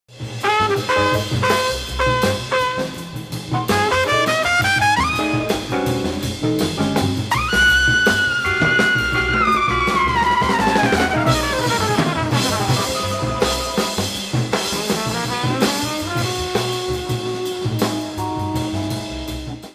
LIVE AT ROTTERDAM, THE NETHERLAND 11/09/1969
サウンドボード録音
圧縮された既発盤の音質と比べると高音部から低音部まで、質がまったく違う
艶のあるリアルなステレオ感でお聴き頂けます。